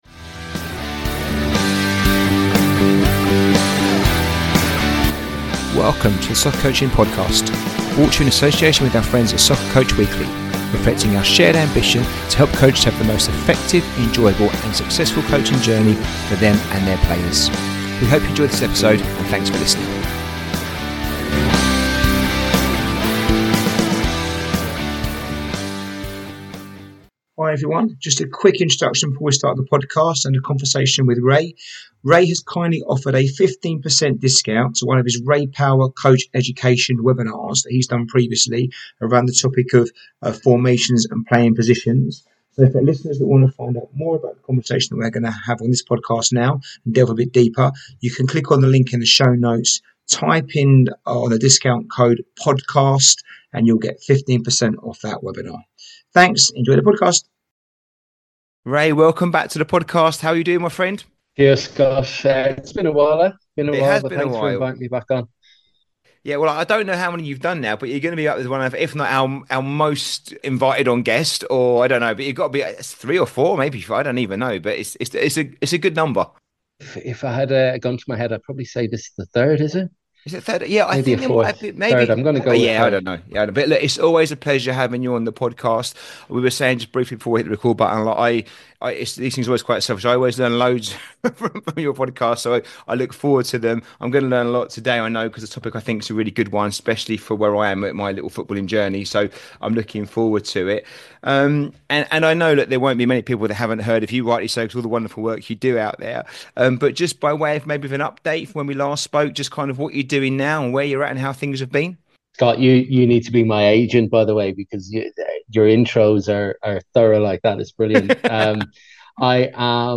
The conversation illuminates crucial distinctions between formations and playing styles, using the example of Mourinho's Chelsea and Guardiola's Barcelona both using 4-3-3 formations but producing dramatically different football.